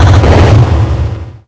Techmino/media/effect/chiptune/clear_6.ogg at 3226c0c831ec9babe3db1c1e9db3e9edbe00a764
新增消5/6音效